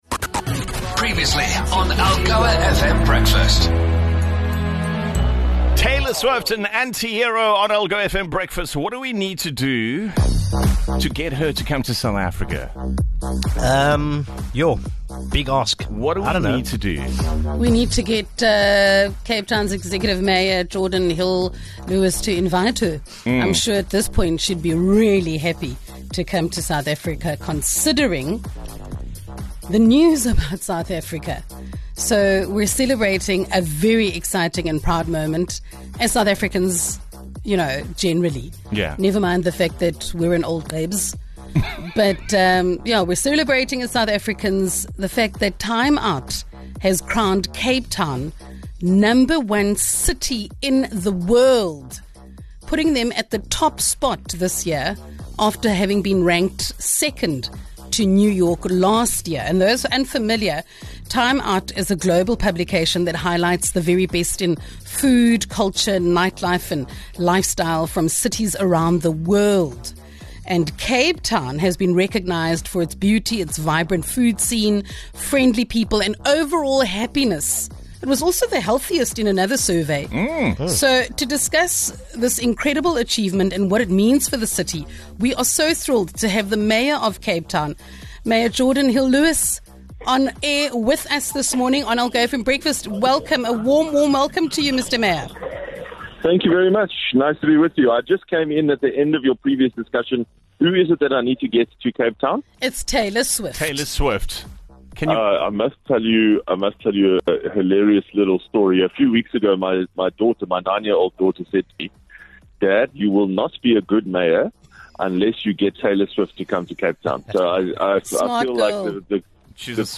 Behind the engaging smile of Cape Town's Mayor is a hard-working individual whose city has been crowned Time Out's best city in the world. An inspiring chat that will leave you wishing there were more mayors like Hill-Lewis.